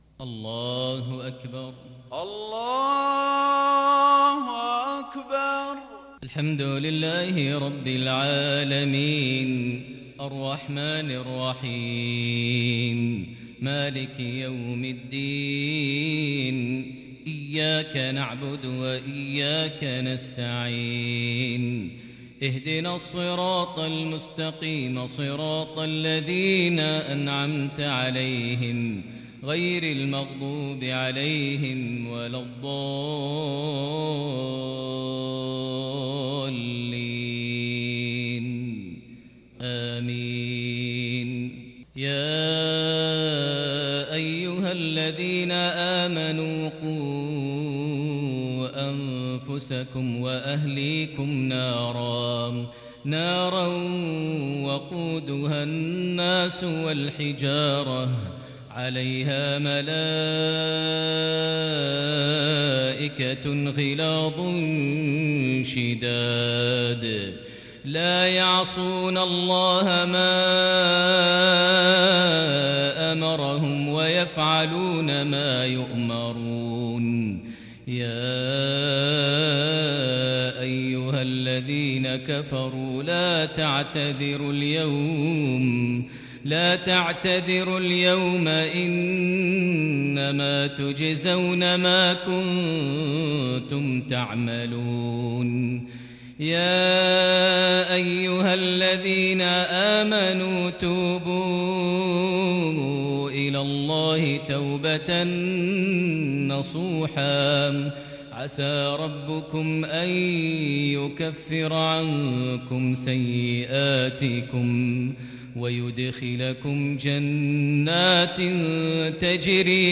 صلاة المغرب للشيخ ماهر المعيقلي 23 صفر 1442 هـ
تِلَاوَات الْحَرَمَيْن .